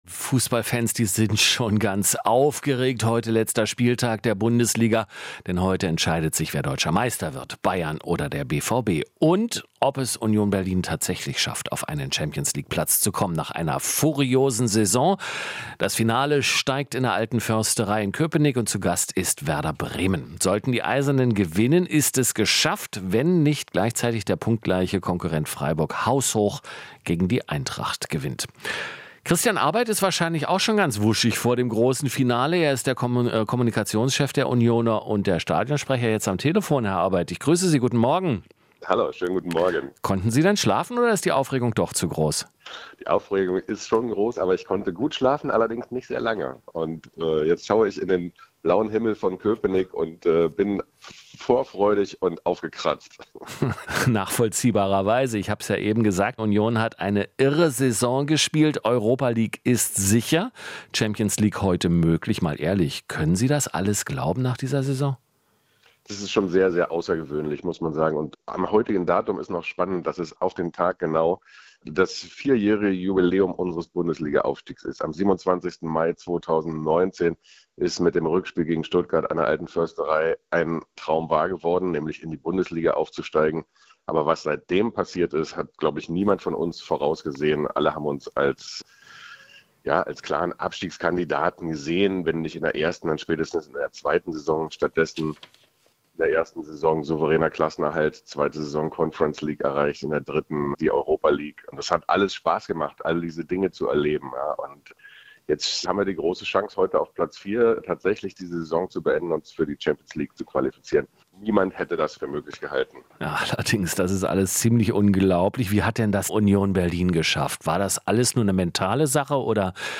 Interview - Schafft Union Berlin die Qualifikation für die Champions League?